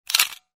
Camera.wav